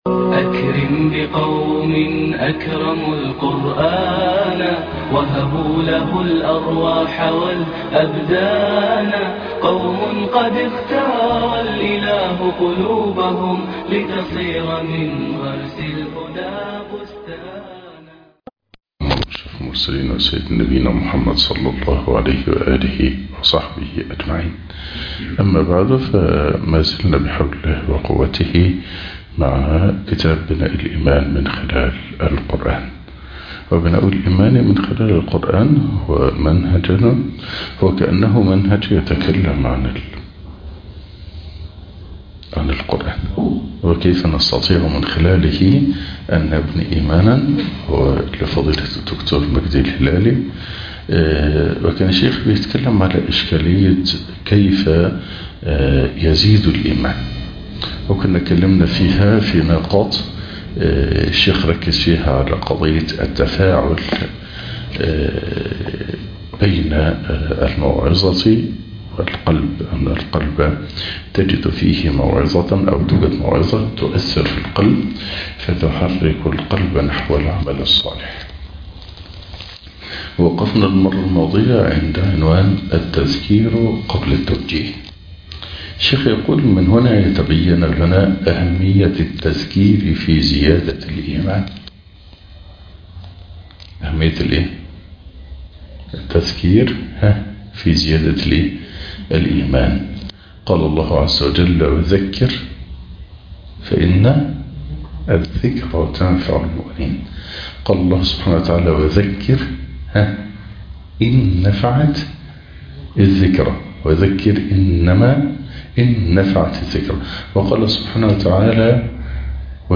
بناء الإيمان من خلال القرآن -الدرس الثامن- أهمية التذكير في زيادة الإيمان